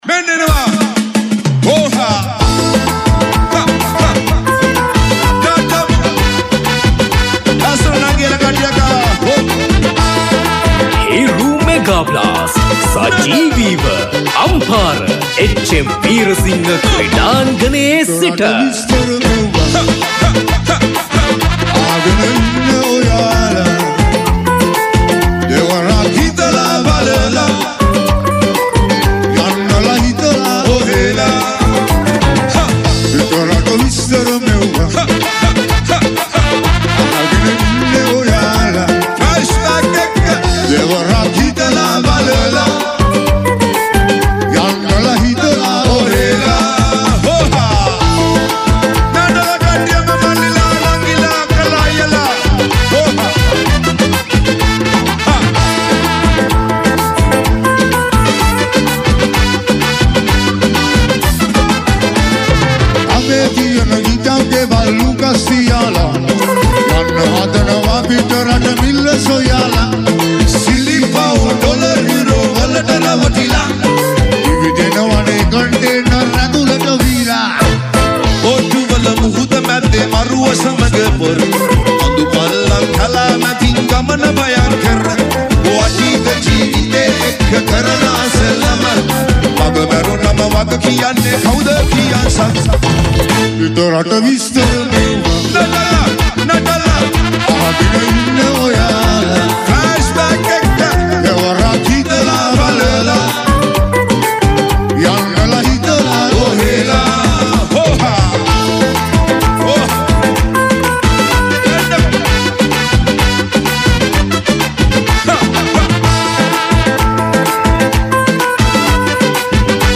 Category: Live Shows